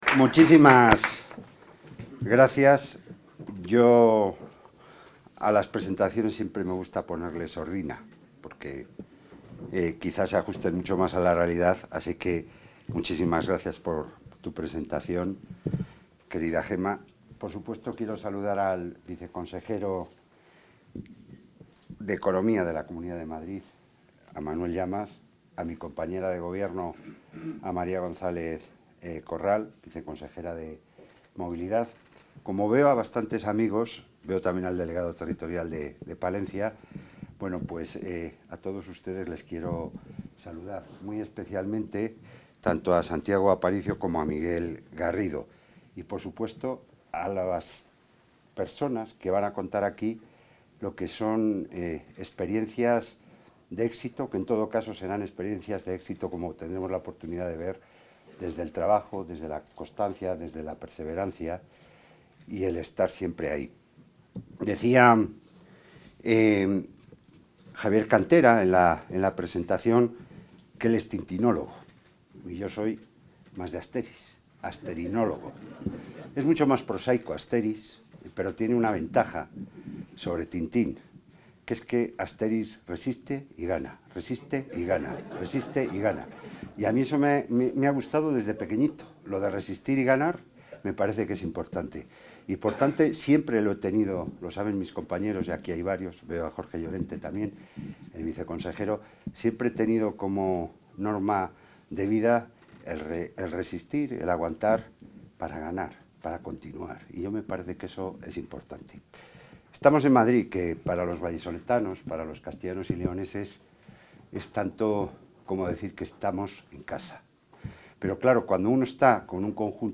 Intervención del consejero.
El consejero de la Presidencia, Jesús Julio Carnero, ha inaugurado el `Encuentro empresarial: Castilla y León – Madrid´, donde han estado presentes varias empresas de ambas comunidades que operan en Madrid, y al mismo tiempo las casas regionales castellano y leonesas en la Comunidad de Madrid.